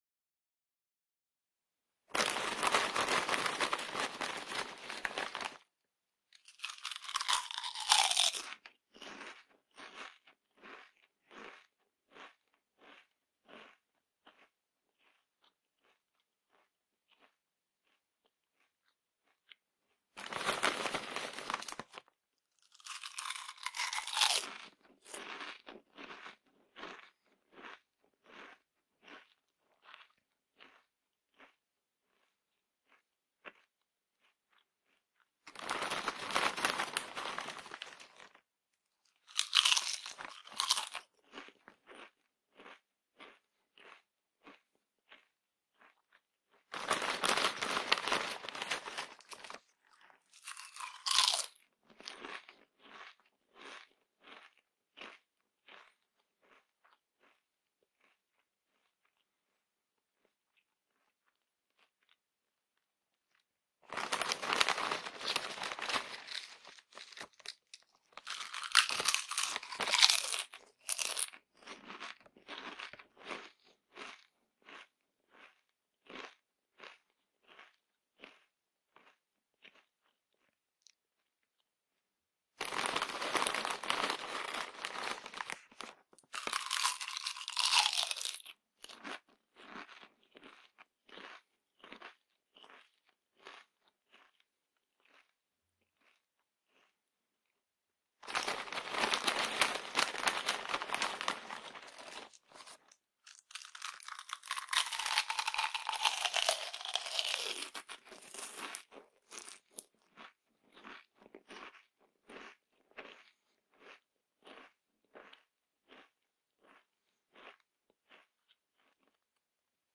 دانلود صدای خوردن چیپس 2 از ساعد نیوز با لینک مستقیم و کیفیت بالا
جلوه های صوتی